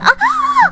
Worms speechbanks
ow2.wav